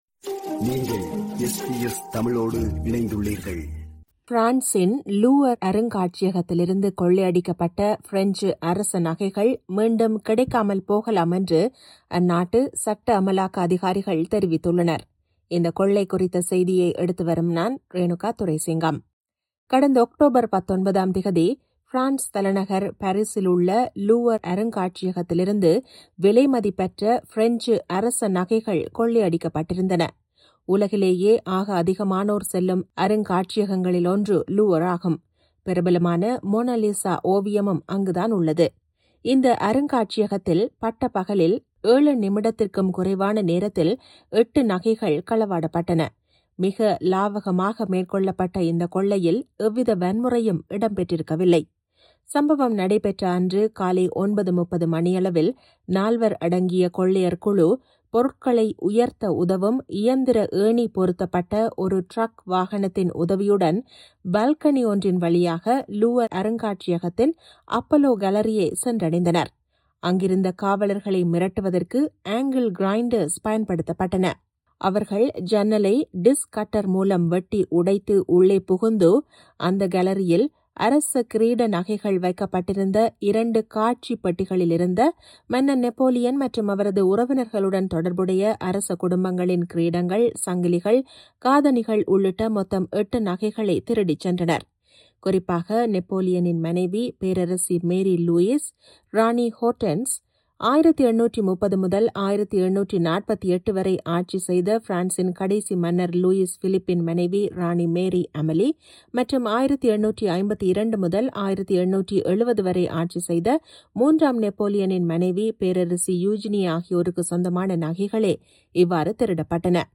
பாரிஸின் லூவர் (Louvre) அருங்காட்சியகத்திலிருந்து கொள்ளையடிக்கப்பட்ட பிரெஞ்சு அரச நகைகள் மீண்டும் கிடைக்காமல் போகலாம் என்று அந்நாட்டுச் சட்ட அமலாக்க அதிகாரிகள் தெரிவித்துள்ளனர். இக்கொள்ளை குறித்த செய்தியை எடுத்துவருகிறார்